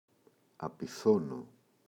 απιθώνω [api’θono]